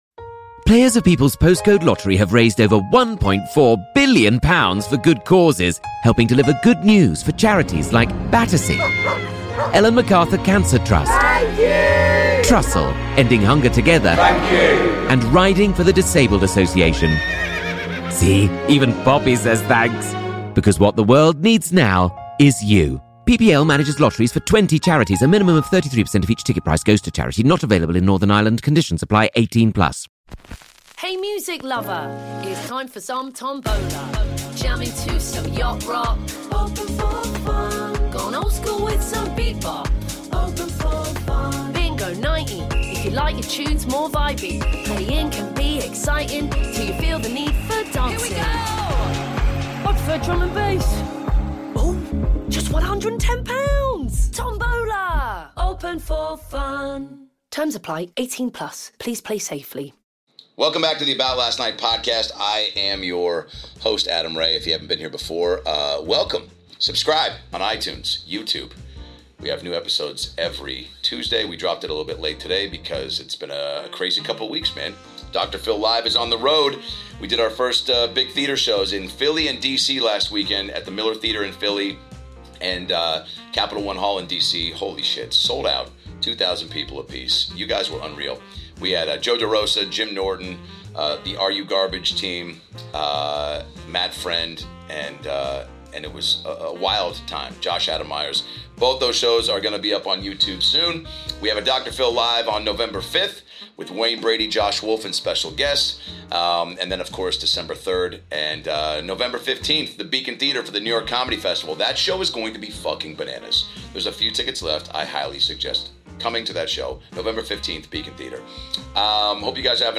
Dr Phil LIVE! is back and coming to you from where it all started at The Comedy Store in Los Angeles, California. Jump in for unstoppable funny with Adam Carolla, Fortune Feimster, Chris Distegano.